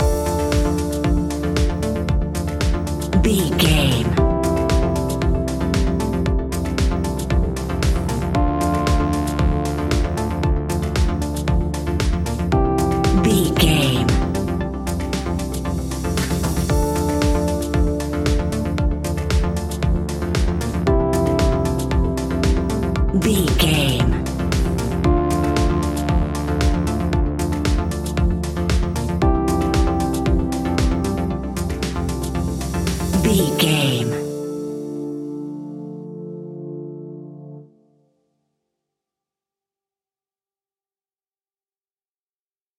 Aeolian/Minor
funky
groovy
uplifting
driving
energetic
drum machine
electric piano
synthesiser
electro house
funky house
house music
synth leads
synth bass